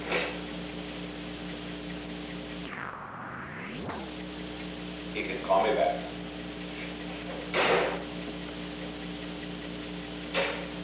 The Old Executive Office Building taping system captured this recording, which is known as Conversation 314-005 of the White House Tapes. Nixon Library Finding Aid: Conversation No. 314-5 Date: January 13, 1972 Time: Unknown between 3:41 pm and 3:50 pm Location: Executive Office Building The President talked with the White House operator Return call by unknown person